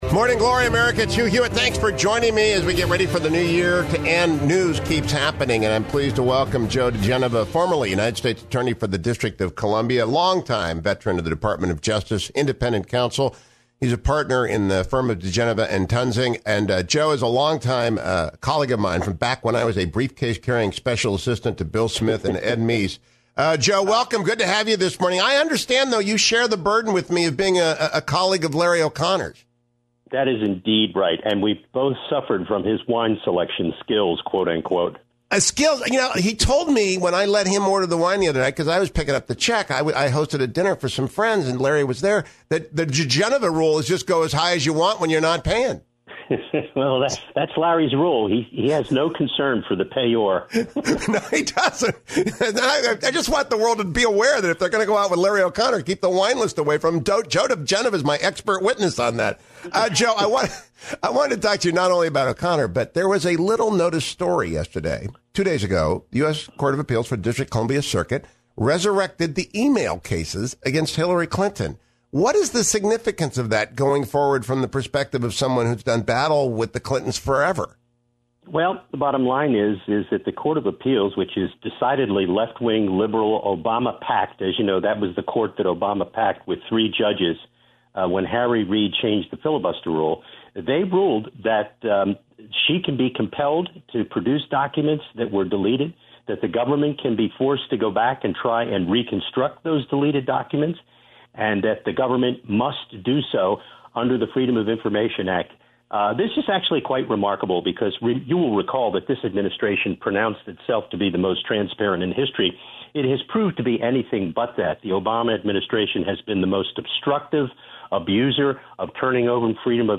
Former United States Attorney and Independent Counsel Joe DiGenova joined me this morning.